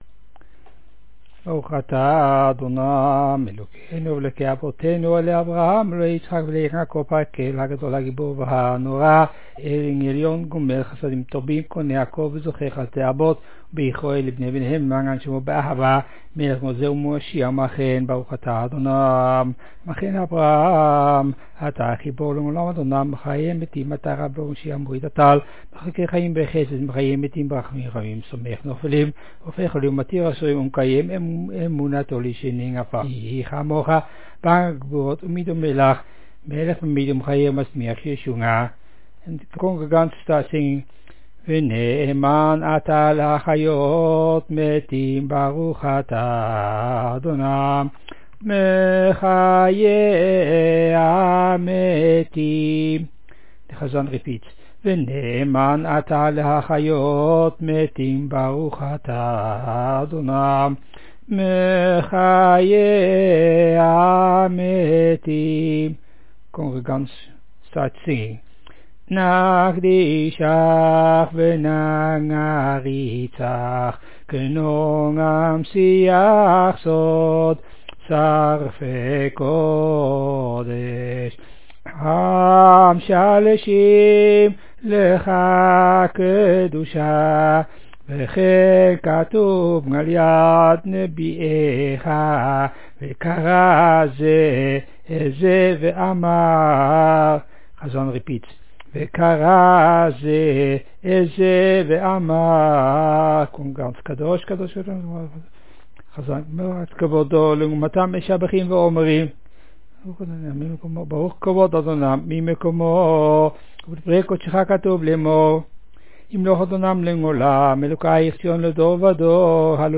Description: 8 Sephardic tunes from Amsterdam
JBS-mincha shabbat  abot-kedusha.mp3